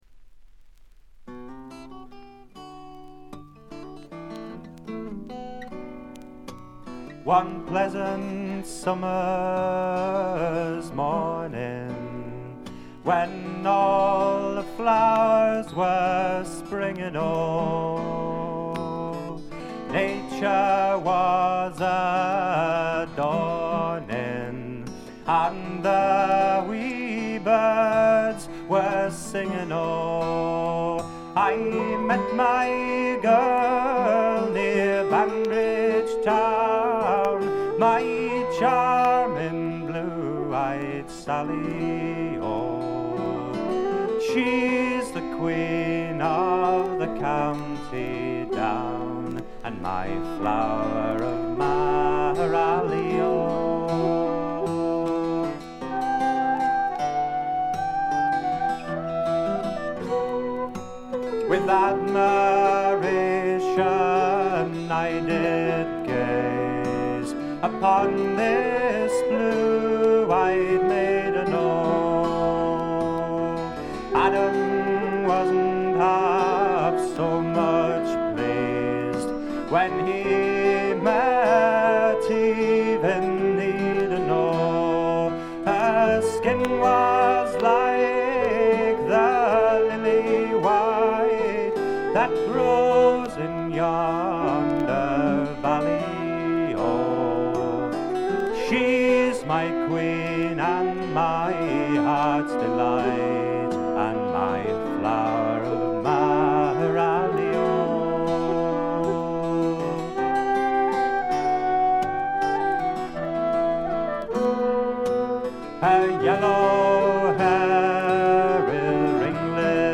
見た目に反してバックグラウンドノイズやチリプチがそこそこ出ます。ところどころで散発的なプツ音。
本作のもの悲しい笛の音や寒そうな感覚は、おなじみのアイリッシュ・トラッドのような感じです。
アコースティック楽器のみで、純度の高い美しい演奏を聴かせてくれる名作です。
試聴曲は現品からの取り込み音源です。
Accordion, Piano, Fiddle
Banjo, Guitar, Vocals
Flute, Whistle [s]